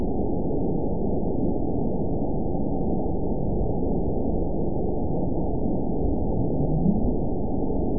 event 922868 date 04/29/25 time 13:31:03 GMT (1 month, 2 weeks ago) score 9.32 location TSS-AB02 detected by nrw target species NRW annotations +NRW Spectrogram: Frequency (kHz) vs. Time (s) audio not available .wav